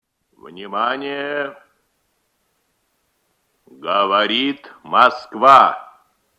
На этой странице собраны архивные записи голоса Юрия Левитана — символа эпохи.
Качество звука восстановлено, чтобы передать мощь и тембр легендарного диктора.